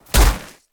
Sfx_creature_snowstalker_walk_01.ogg